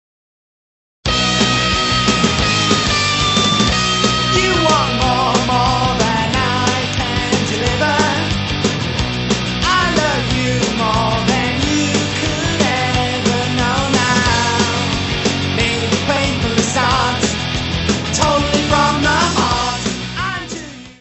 guitarra, voz
bateria, percussão
baixo, guitarra.
Music Category/Genre:  Pop / Rock